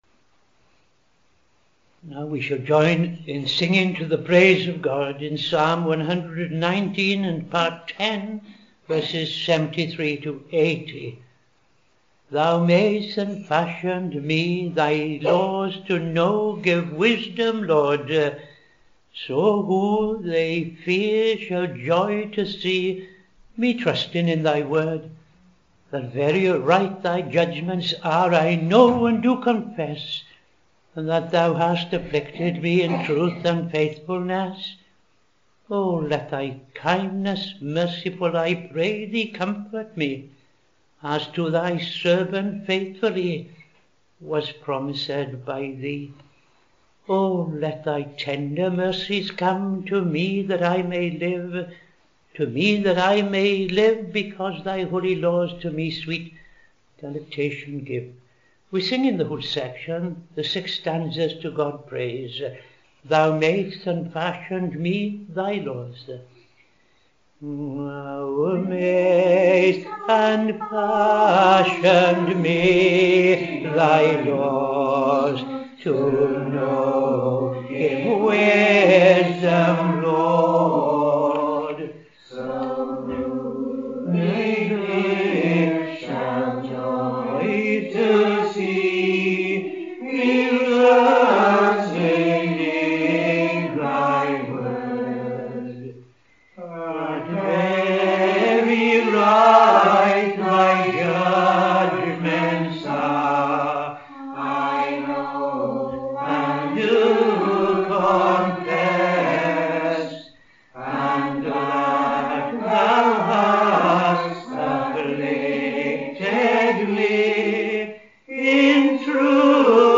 Evening Service - TFCChurch
5.00 pm Evening Service Opening Prayer and O.T. Reading I Chronicles 10:1-14